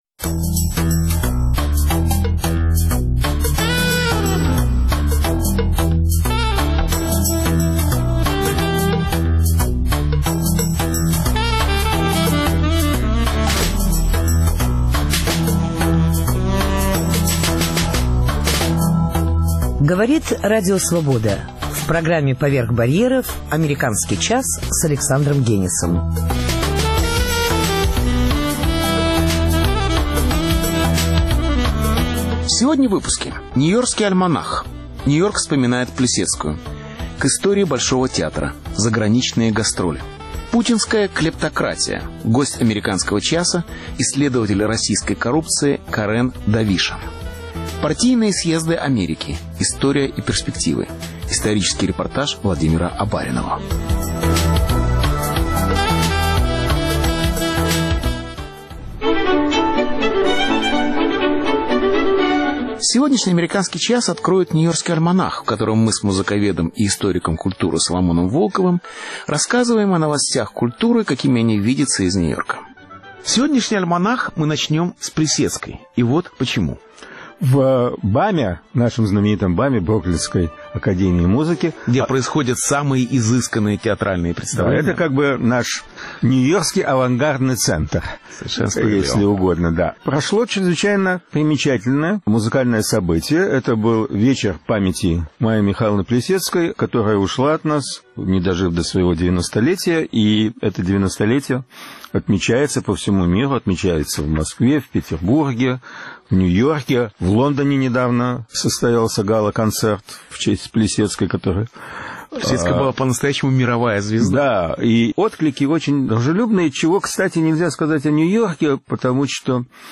Исторический репортаж